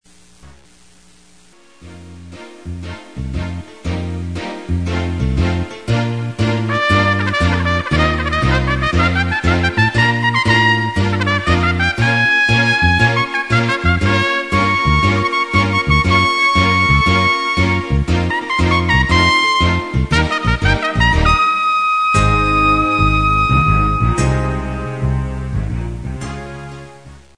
ordinary Bb trumpet